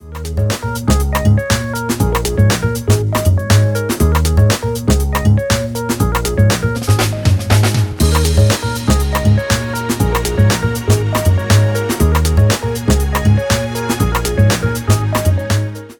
Some light delay was added, the sample was trimmed to size and a small fade was executed to edit the end of the sample. The end result is a pretty realistic drum fill, that adds a human feel to the mix.
The new sample treated and in place.